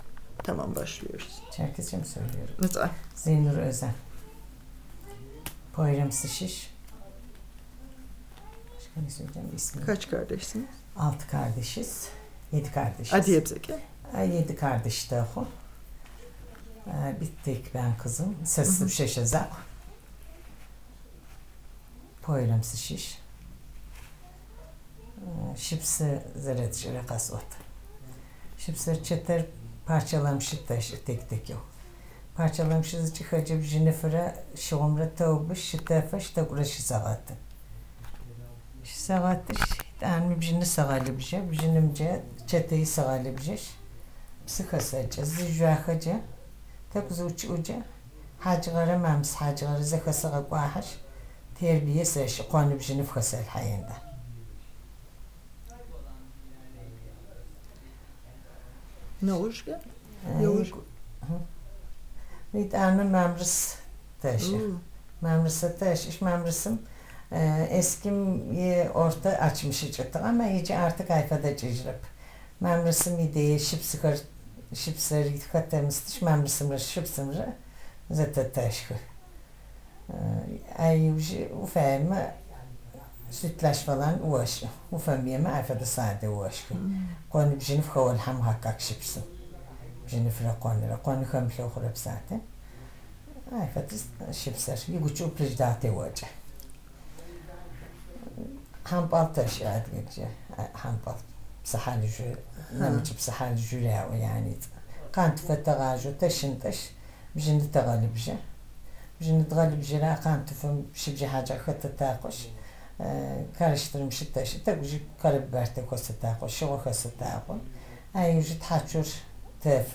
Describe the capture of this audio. digital wav file recorded at 44.1 kHz/16 bit on Zoom H2 solid state recorder Eskişehir -Poyra-Turkey